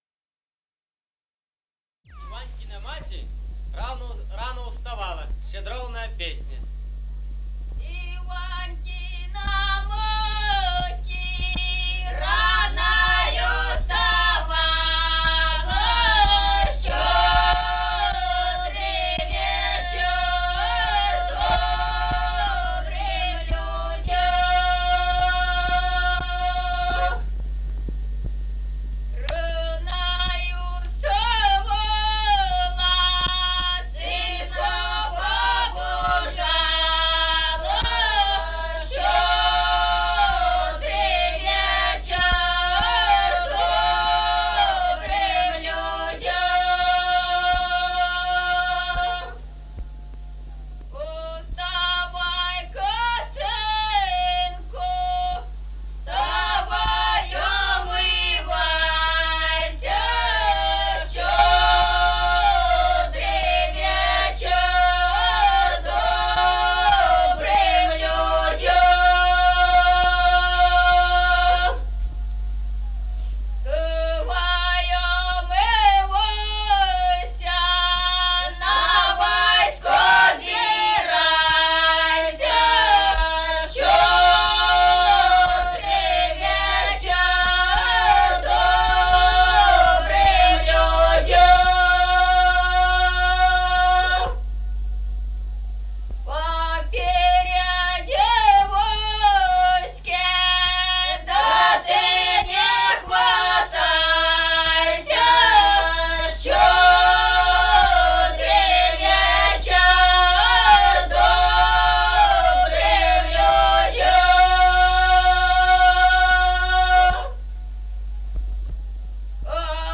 Музыкальный фольклор села Мишковка «Иванькина мати», щедровная.